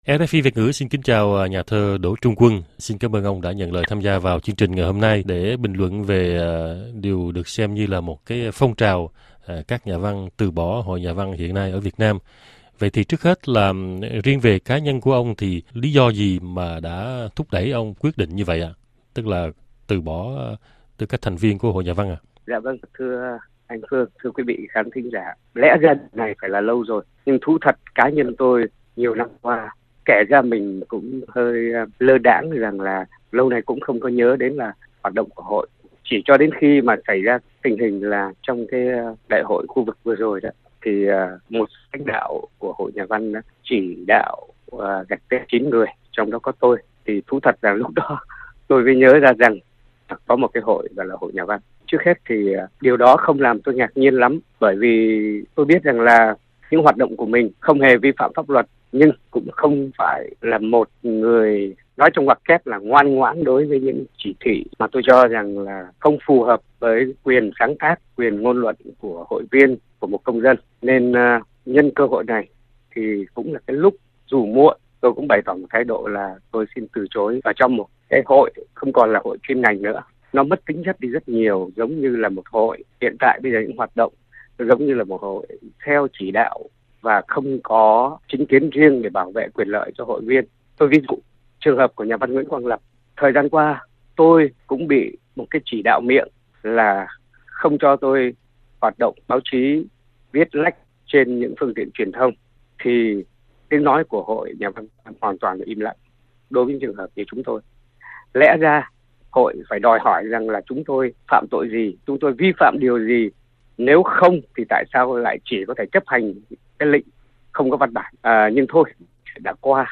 Tải để nghe nhà thơ Đổ Trung Quân tại Sài Gòn
Nhân đây, RFI Việt Nam đã phỏng vấn một trong những người tham gia tuyên bố từ bỏ Hội Nhà văn kể từ ngày 11/05/2015, đó là nhà thơ Đỗ Trung Quân từ Sài Gòn.